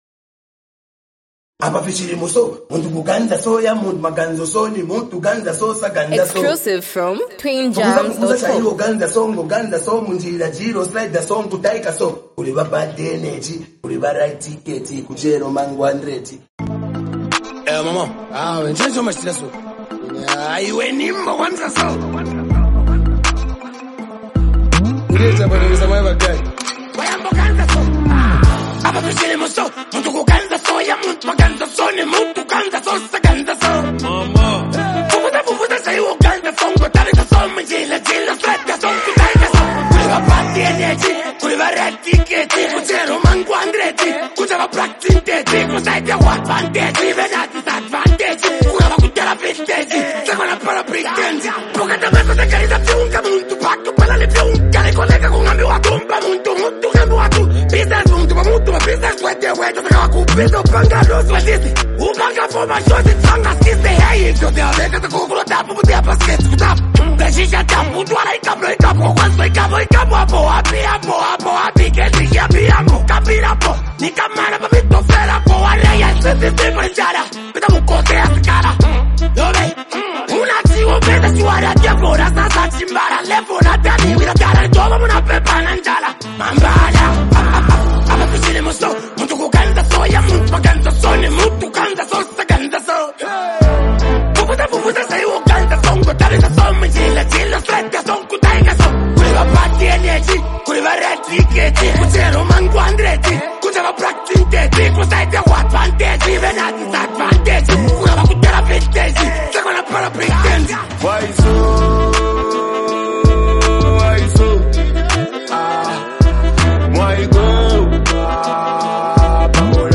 an emotionally charged track
Through melodic flows and expressive vocals